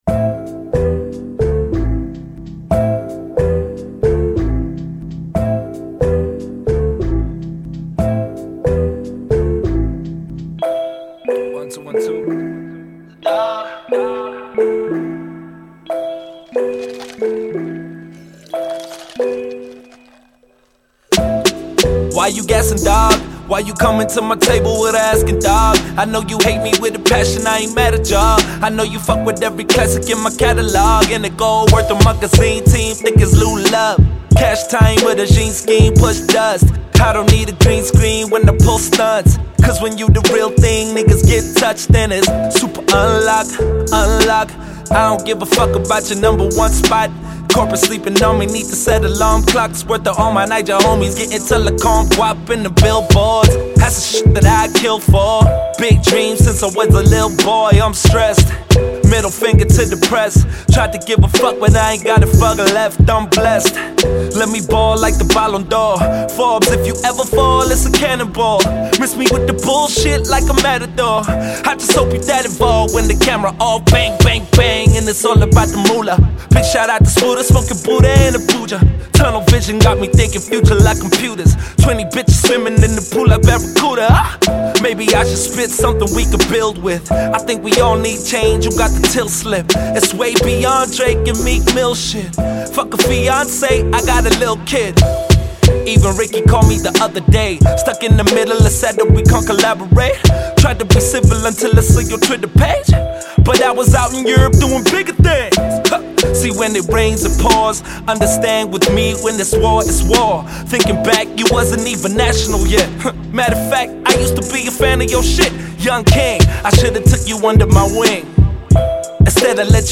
South African rapper